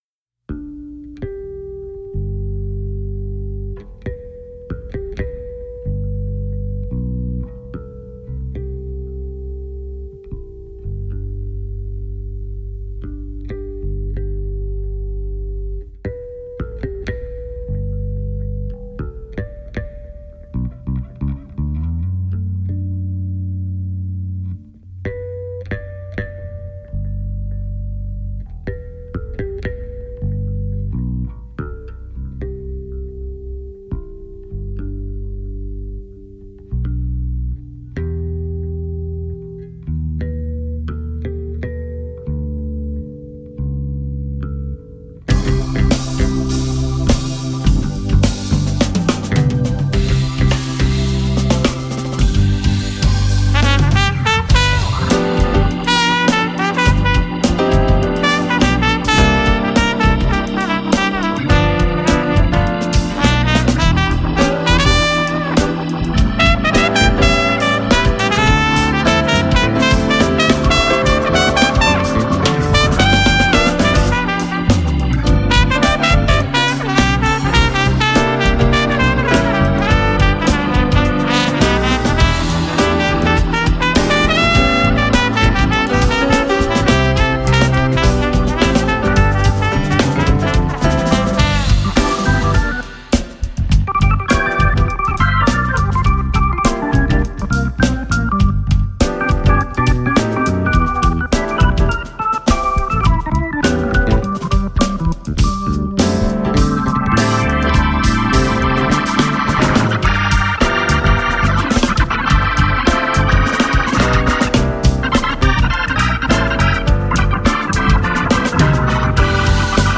baskytara, kontrabas